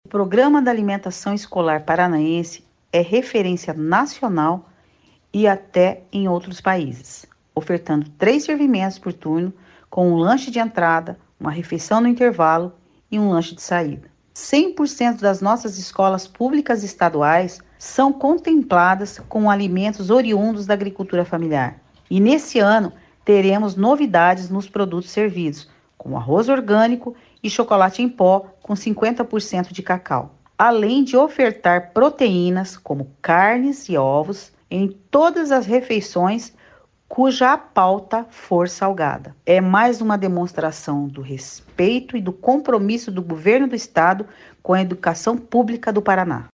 Sonora da diretora-presidente do Fundepar, Eliane Teruel Carmona, sobre a entrega da primeira remessa de 2024 da alimentação escolar para as instituições de ensino estaduais